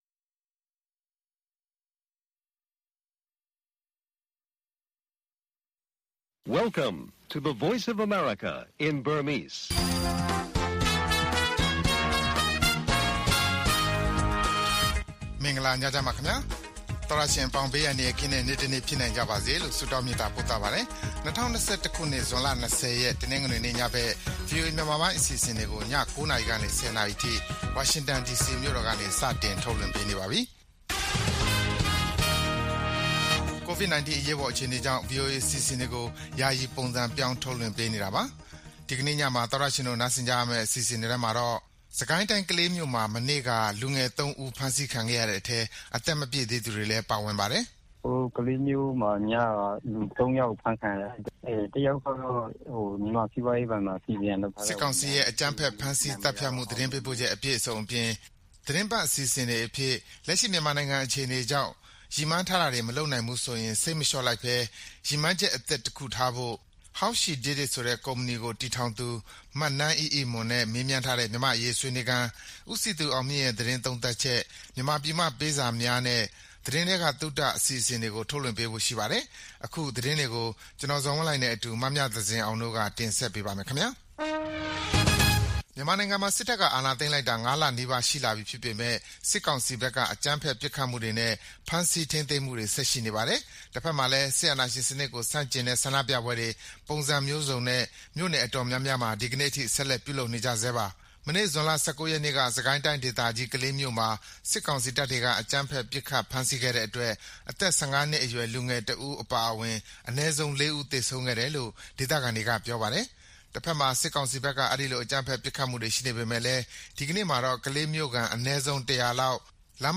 VOA ညပိုင်း ၉း၀၀-၁၀း၀၀ တိုက်ရိုက်ထုတ်လွှင့်ချက်